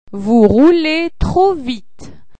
Vous [à un homme]   loak